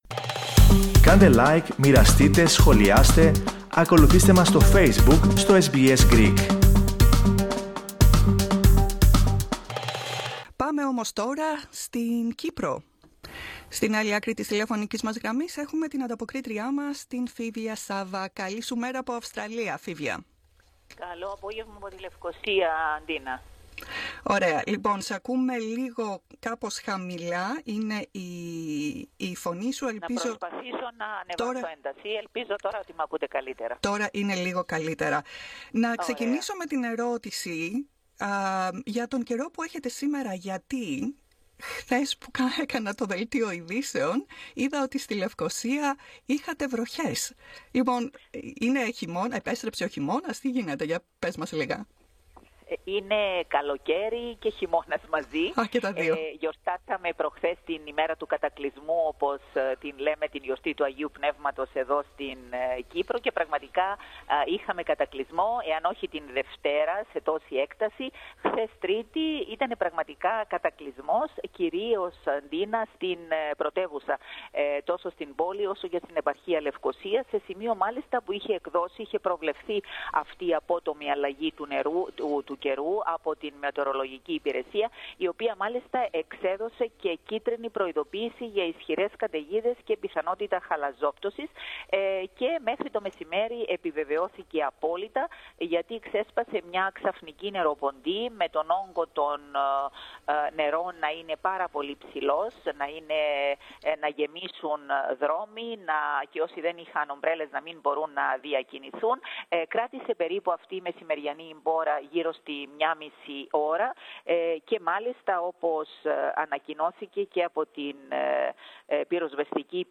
Ακούστε, ολόκληρη την ανταπόκριση, από την Κύπρο, πατώντας το σύμβολο στο μέσο της κεντρικής φωτογραφίας.